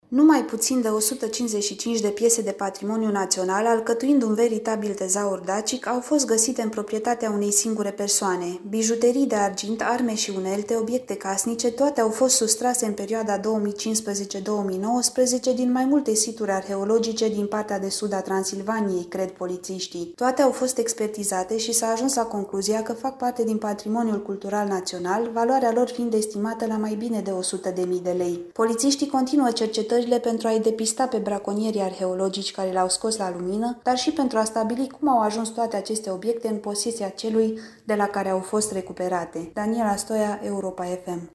Cele peste 150 de podoabe din argint, arme, unelte și obiecte casnic au fost furate din situri arheologice din sudul Transilvaniei, transmite corespondentul Europa FM.